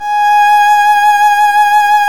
Index of /90_sSampleCDs/Roland - String Master Series/STR_Violin 1-3vb/STR_Vln1 % marc